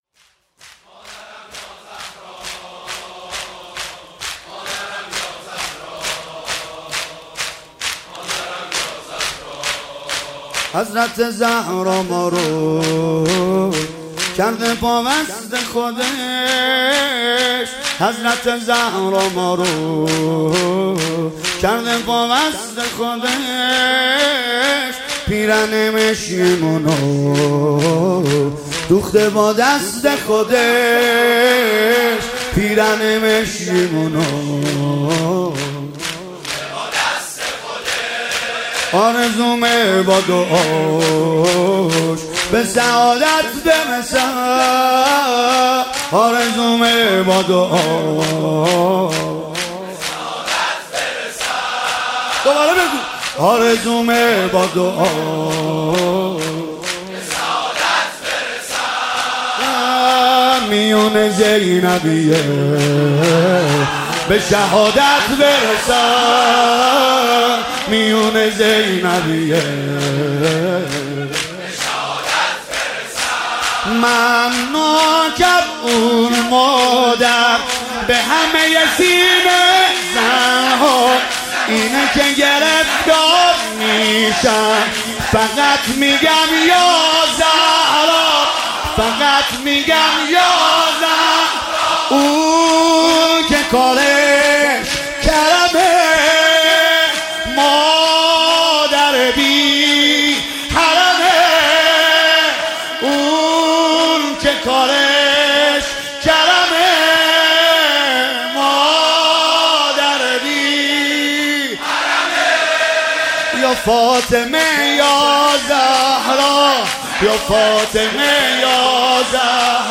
فاطميه 95
رضا نريمانی شور